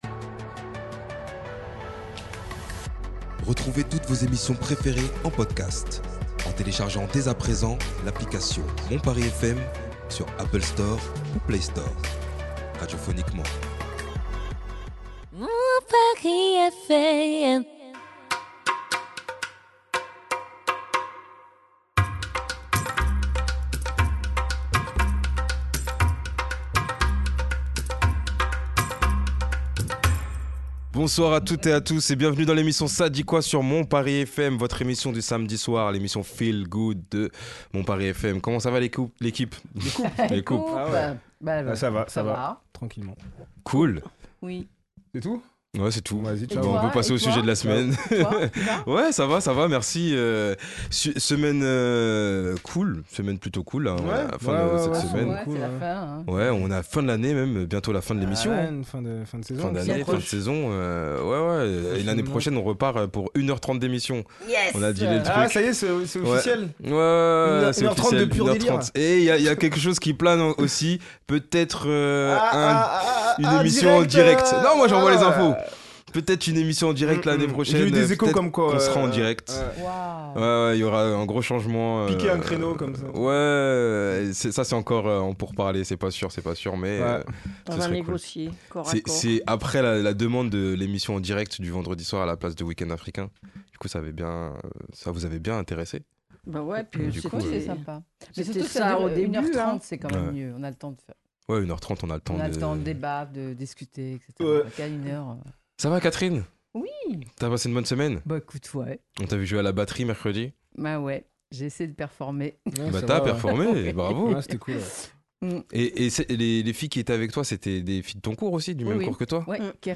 (débat de la semaine)- Jeu des Capitales